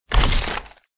Звуки ударов, разрушения
Удар кулаком пробил стену насквозь